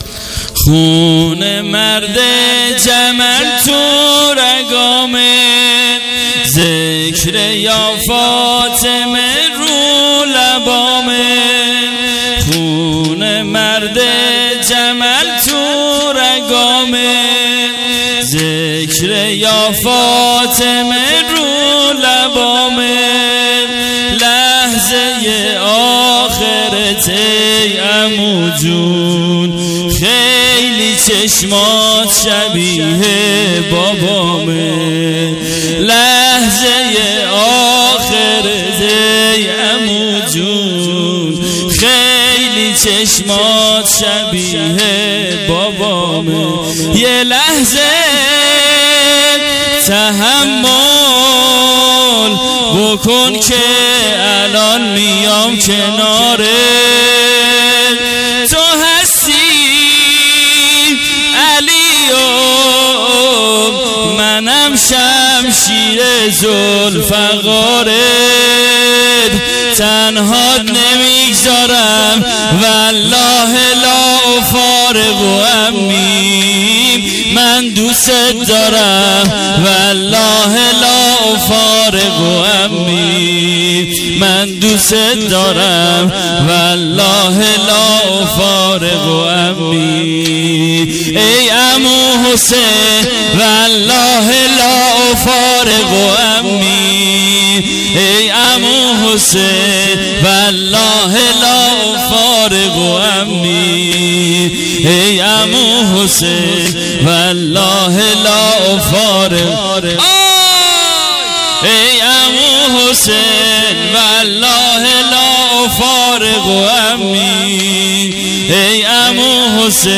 محرم الحرام ۱۴۰۰